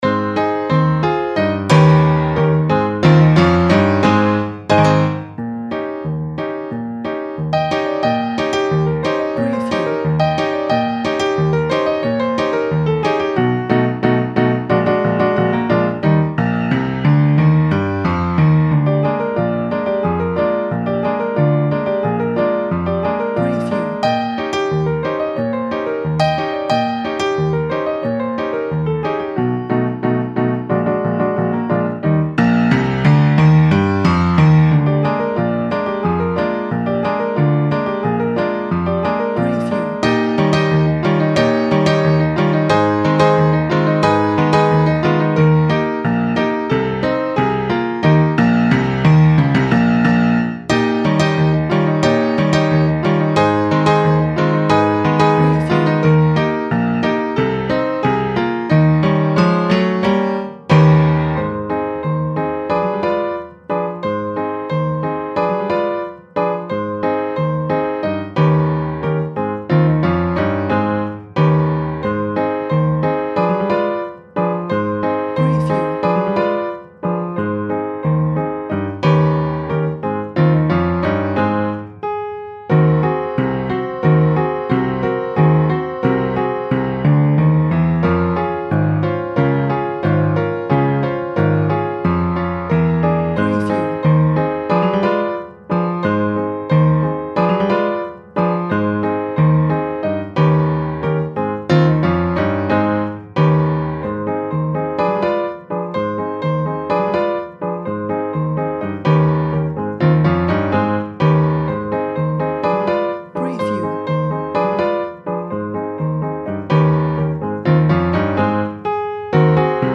Funny mix between Piano Rag and Charleston piano music.